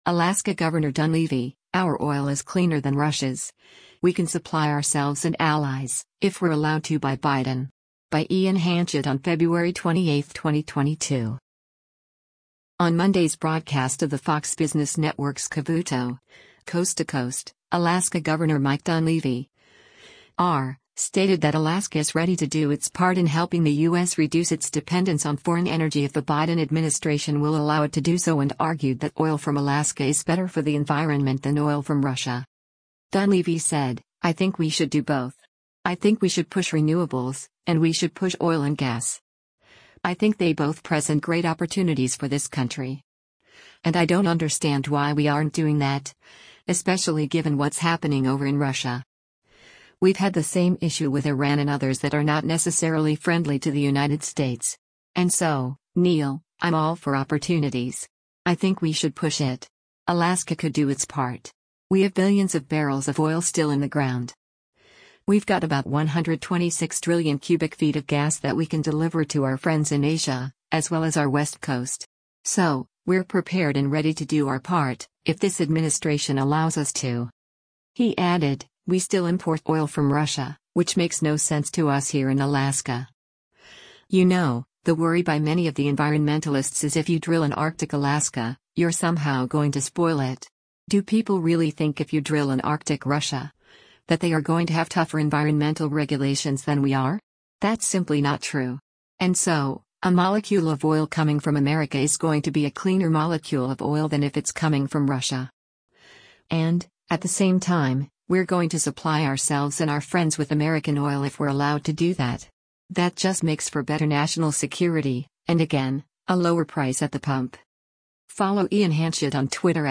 On Monday’s broadcast of the Fox Business Network’s “Cavuto: Coast to Coast,” Alaska Gov. Mike Dunleavy (R) stated that Alaska is ready to do its part in helping the U.S. reduce its dependence on foreign energy if the Biden administration will allow it to do so and argued that oil from Alaska is better for the environment than oil from Russia.